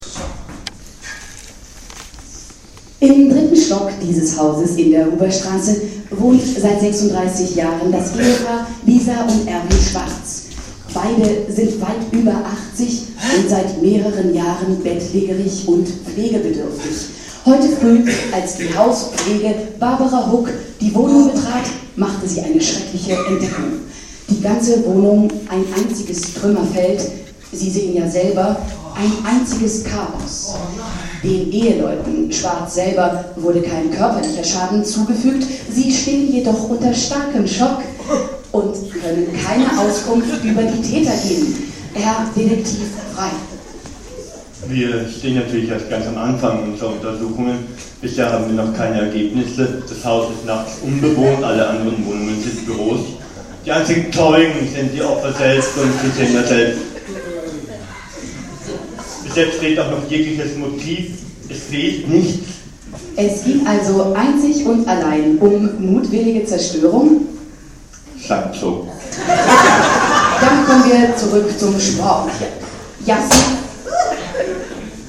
"Radiobericht" zum Einbruch
Die Alterswohnung des bettlägrigen Ehepaares soll überfallen und ausgeraubt worden sein. Der Detektiv mit Sprachfehler gibt Auskunft – Theater am Neumarkt.